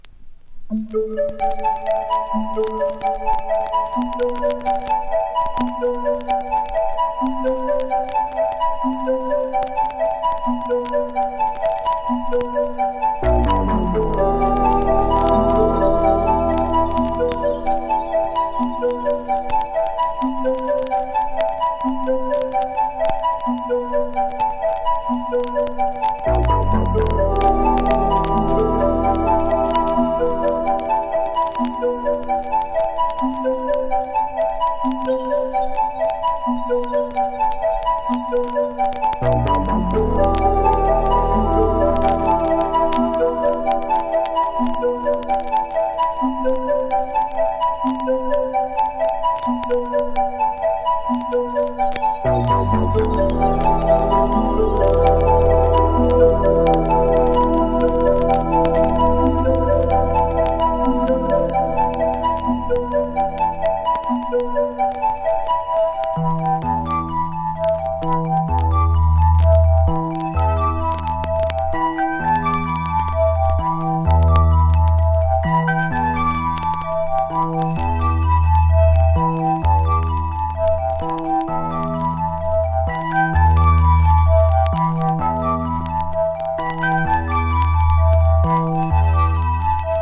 明確なメロディーを導入しつつ、ミニマリズム感覚も融合させた音が後のエレクトロ・ミュージックの方向性の一つとなった名作！
PROGRESSIVE# AMBIENT / EXPERIMENTAL# 80’s ROCK